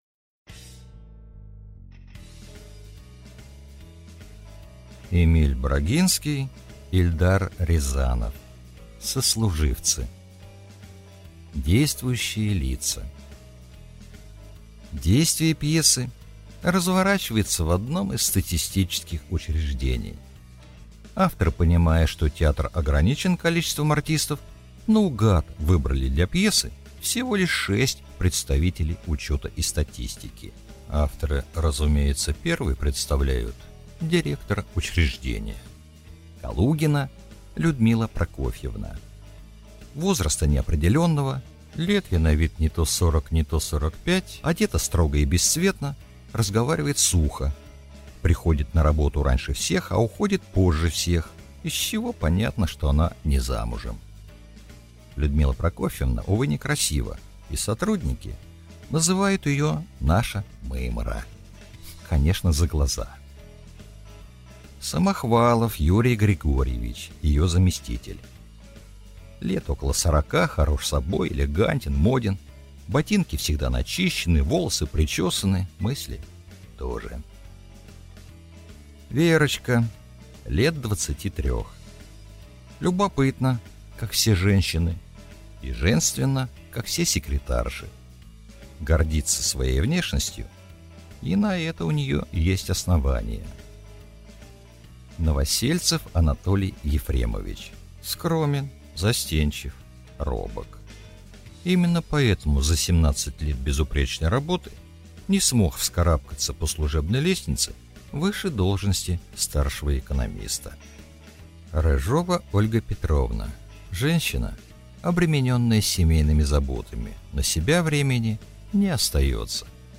Аудиокнига Сослуживцы | Библиотека аудиокниг